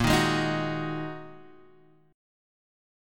Bb7sus4#5 chord